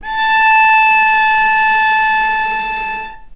vc-A5-mf.AIF